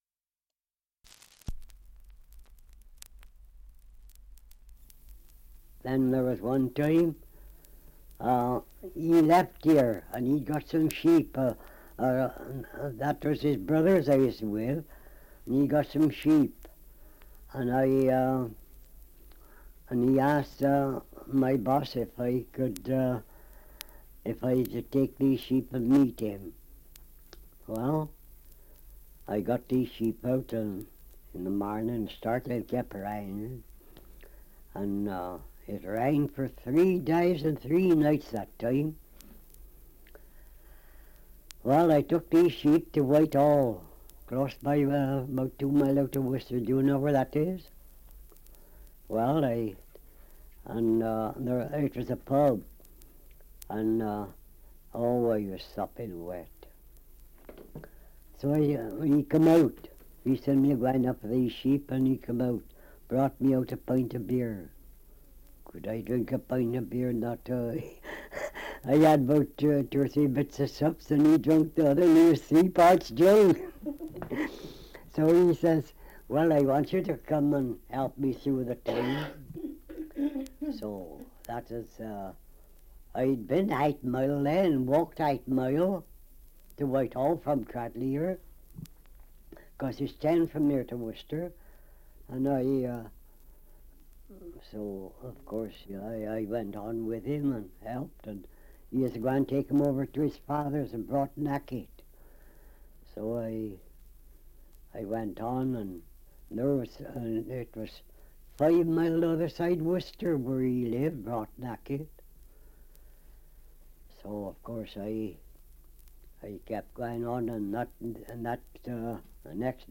Survey of English Dialects recording in Cradley, Herefordshire
78 r.p.m., cellulose nitrate on aluminium